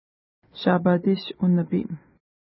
Pronunciation: ʃa:pa:ti:ʃ unəpi:m
Pronunciation